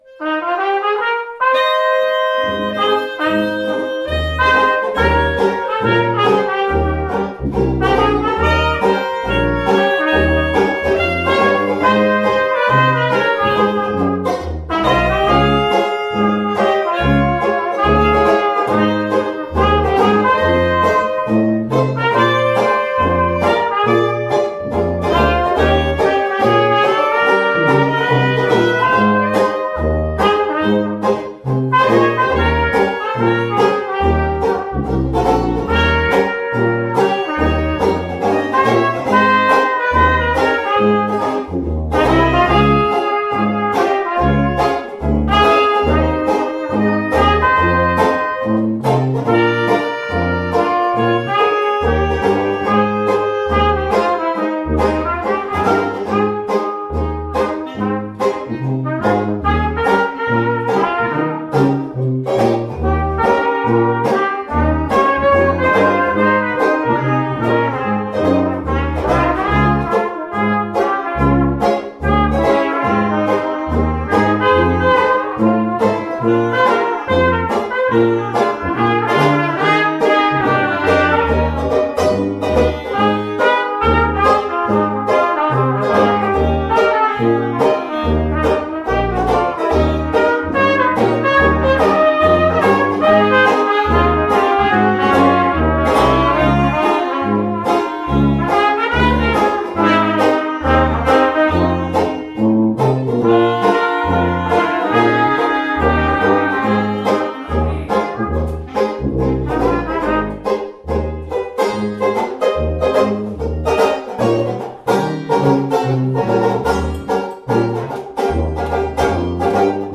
Banjo
Clarinet
Cornet
Trumpet
Tuba